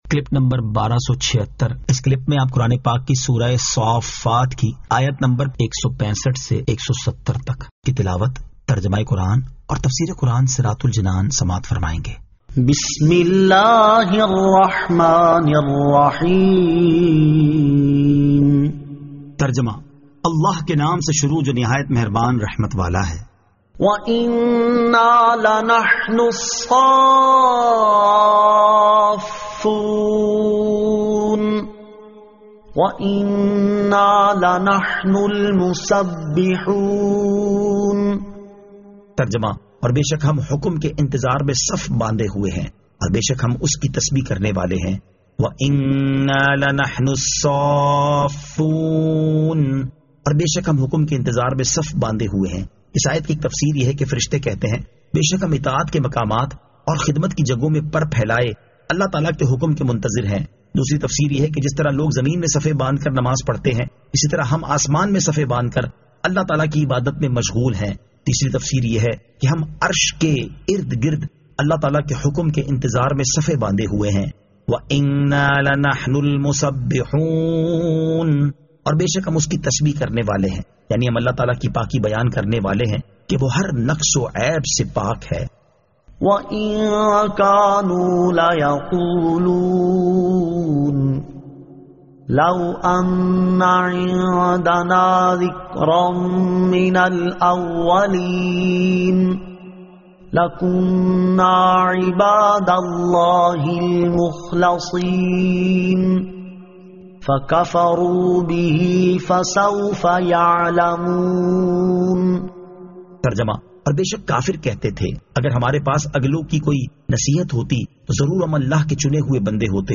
Surah As-Saaffat 165 To 170 Tilawat , Tarjama , Tafseer